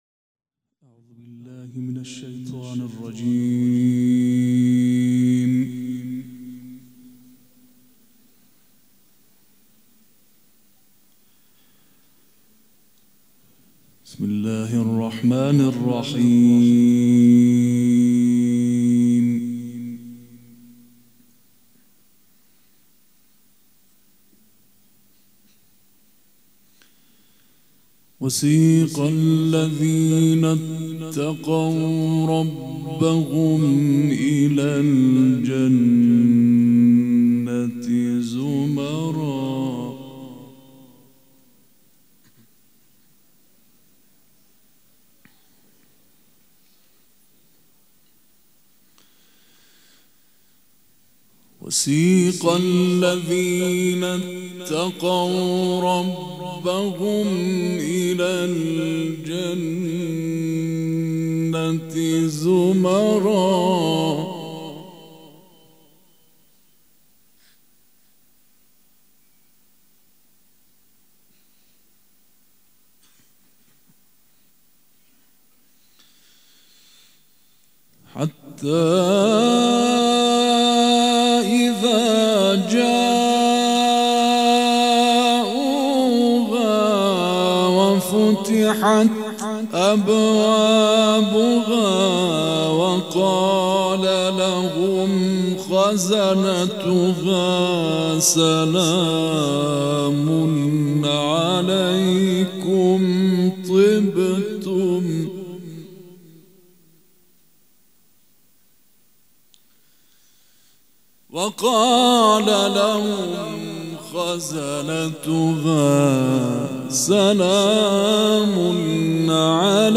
قرائت قرآن
مراسم عزاداری شب چهارم